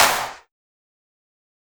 soft-hitclap.wav